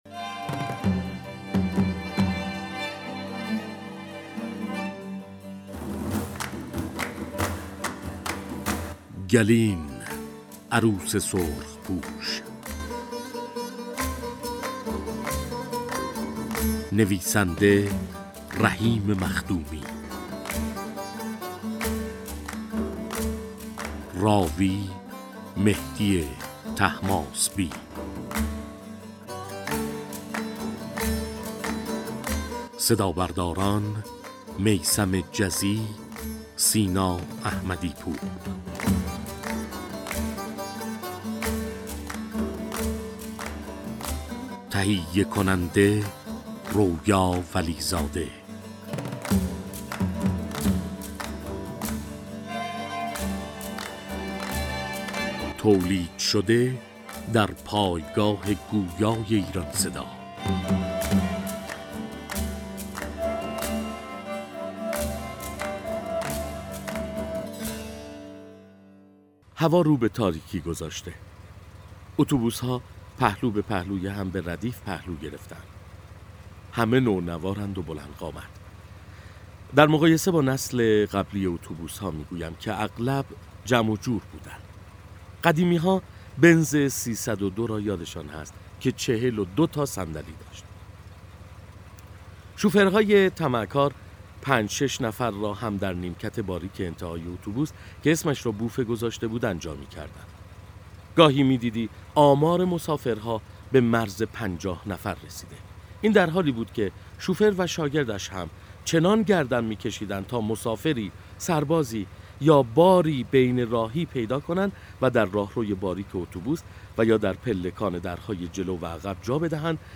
«گلین» نام روستایی در حوالی سنندج است که سال 1360 حوادث تلخ و خونباری را تجربه می کند و گوشه ای از تاریخ رشادت های مردمان کردستان را در دل خود جای داده است. این کتاب توسط «ایران صدا» به کتاب گویا تبدیل شده و نسخه صوتی آن برای مخاطبان نوید شاهد در ادامه مطلب قابل دسترس است.